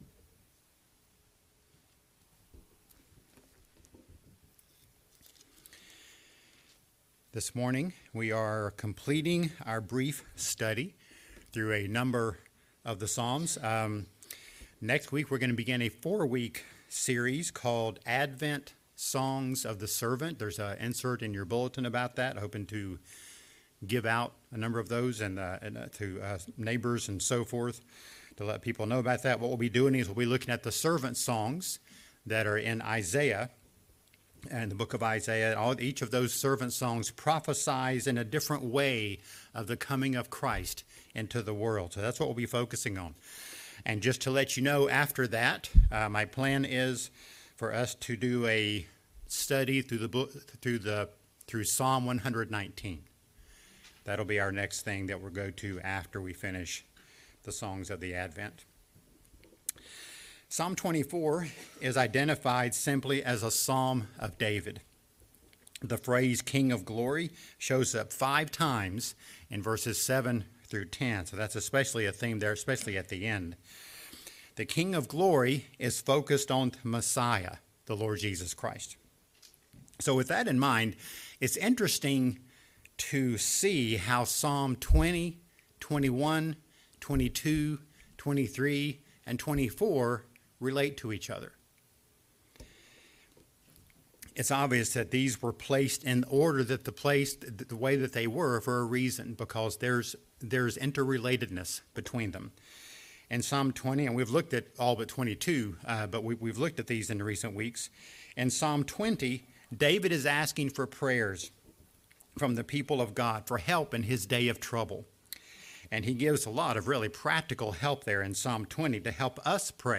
The King of Glory | SermonAudio Broadcaster is Live View the Live Stream Share this sermon Disabled by adblocker Copy URL Copied!